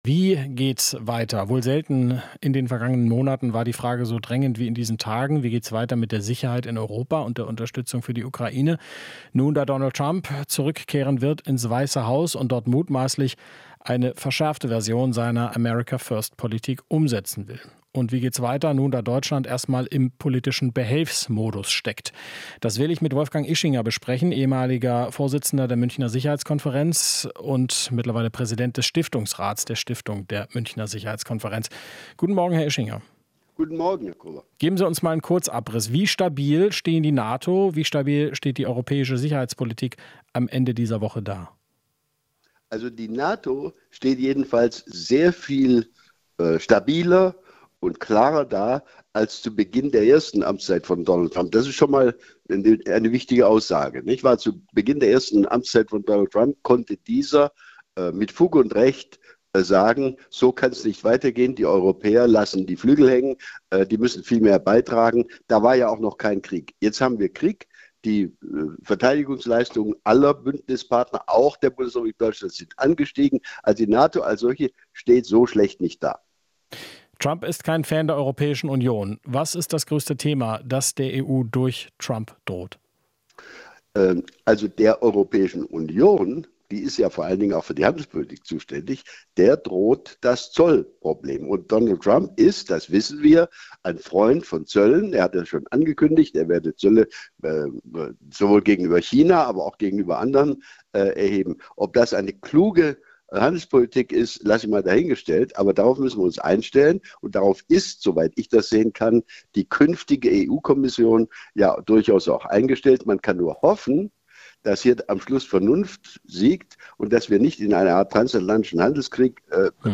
Interview - Ischinger: "Mehr Europa" als Antwort auf Trump und Putin